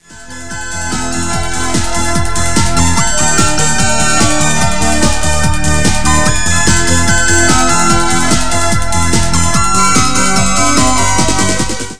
4:50 - 150 bpm - 13 April 1997